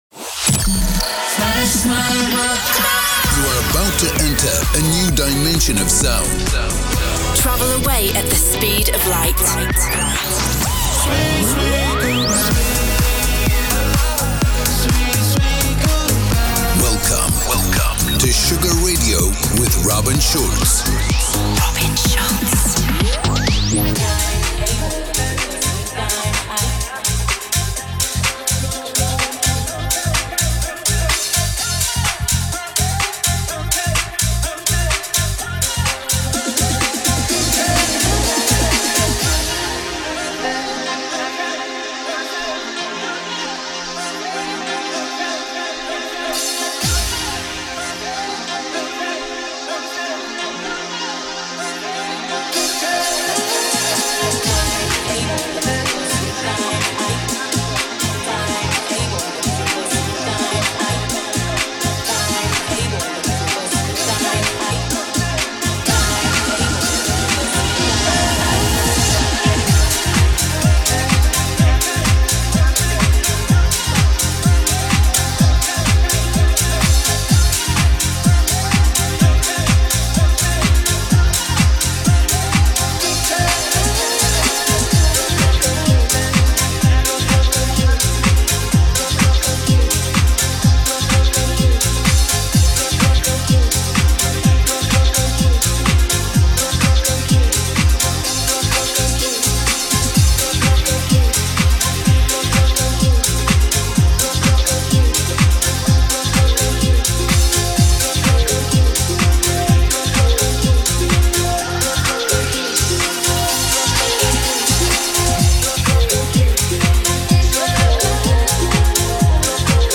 music DJ Mix in MP3 format
Genre: Electro Pop; Duration